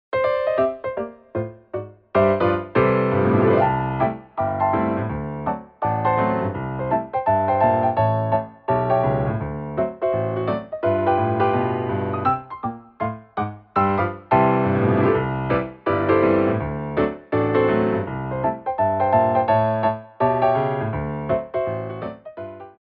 QUICK TEMPO